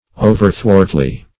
Search Result for " overthwartly" : The Collaborative International Dictionary of English v.0.48: Overthwartly \O"ver*thwart"ly\, adv. In an overthwart manner; across; also, perversely.